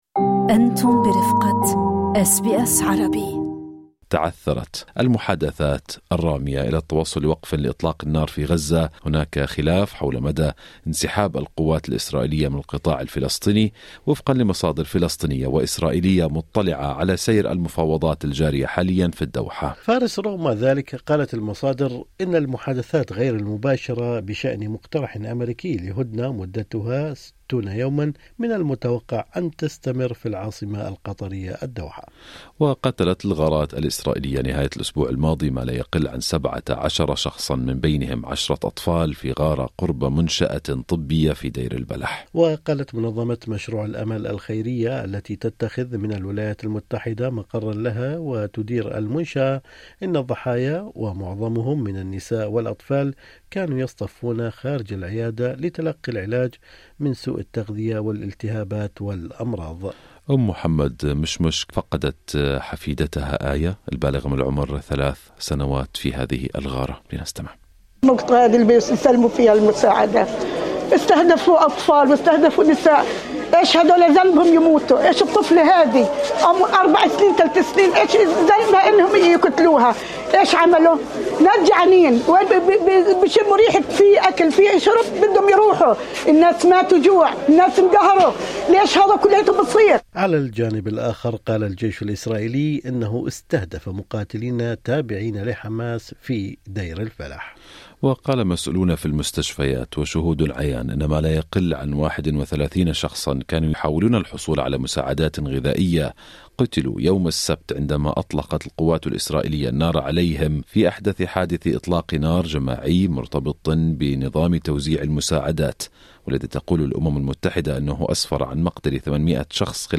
"الجوع ينهش الناس": شهادات من داخل غزة تشير إلى تردي الأوضاع الإنسانية
تحذير: هذا التقرير يحتوي على تفاصيل قد تكون مؤلمة لبعض المستمعين والمتابعين